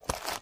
STEPS Dirt, Walk 15.wav